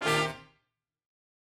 GS_HornStab-Cmin+9sus4.wav